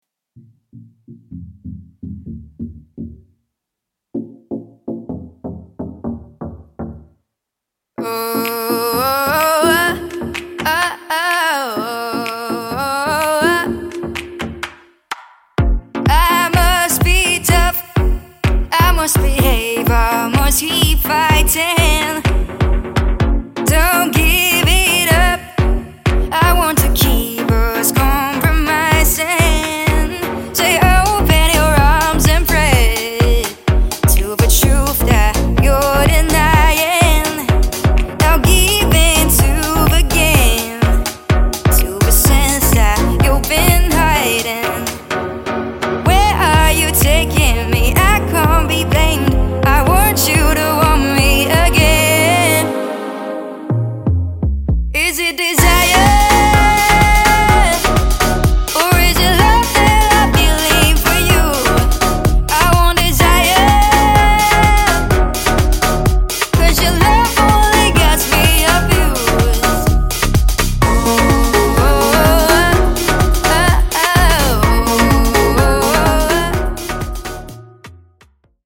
16/12/10 15:46    你的唱法跟olly好像
16/12/07 16:10    这个音色让我想起lean on这首歌